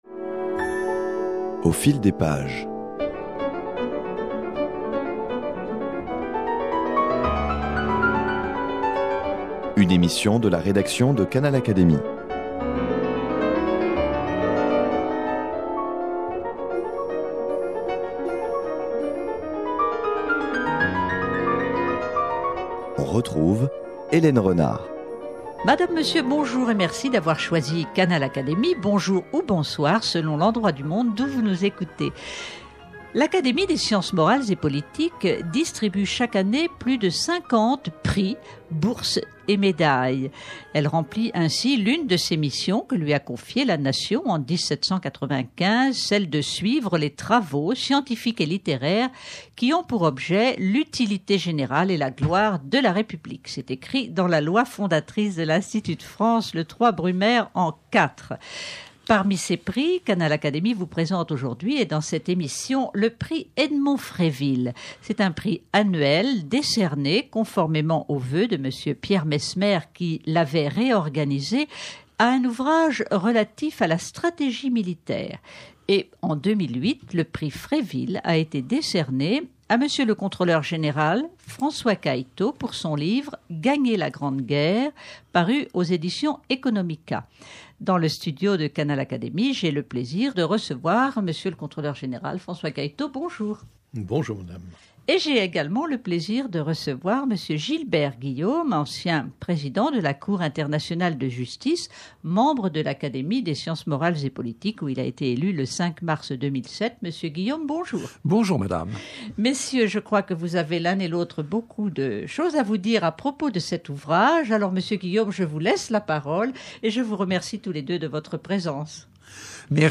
L’auteur du livre Gagner la Grande Guerre, le contrôleur général des armées François Cailleteau, qui a reçu le Prix Edmond Fréville-Pierre Messmer 2008 décerné par l’Académie des sciences morales et politiques, s’entretient ici avec Gilbert Guillaume, membre de cette Académie, sur les stratégies utilisées par les armées en conflit en 14-18 et sur les relations entre politiques et militaires.